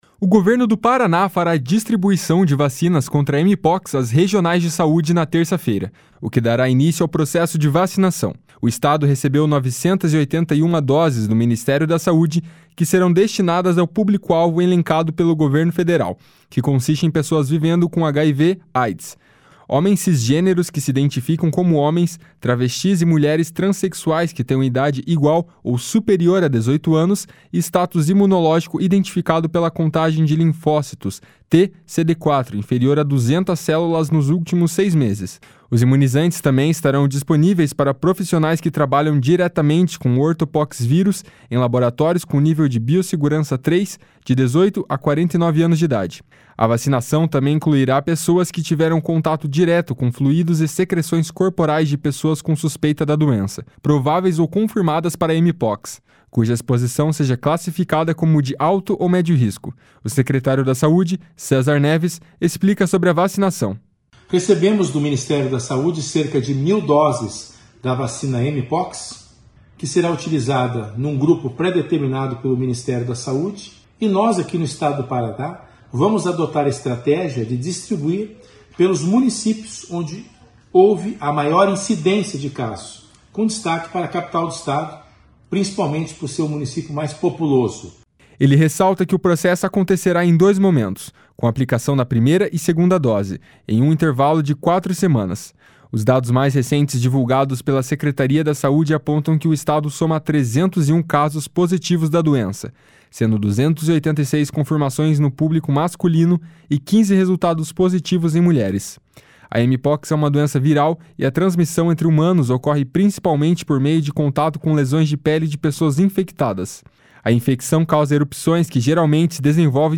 O secretário da Saúde, César Neves, explica sobre a vacinação. // SONORA CÉSAR NEVES //